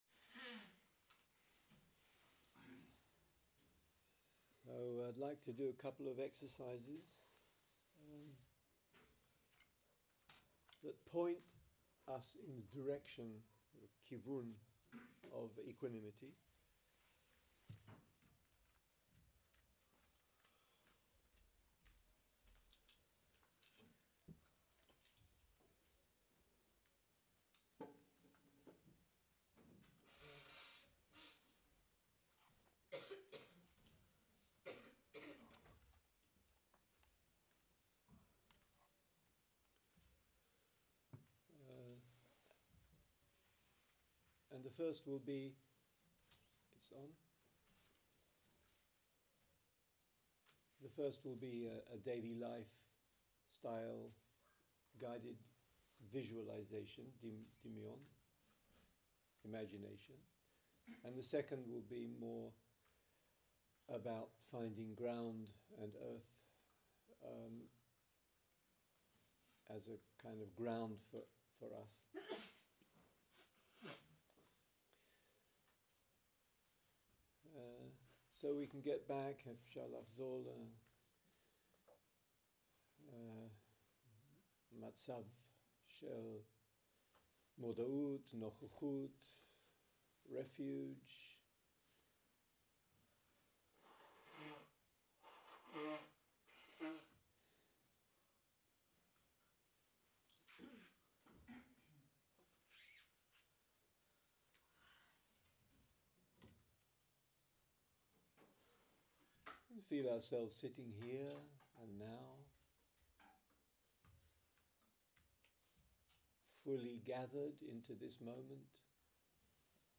סוג ההקלטה: שיחת הנחיות למדיטציה
איכות ההקלטה: איכות גבוהה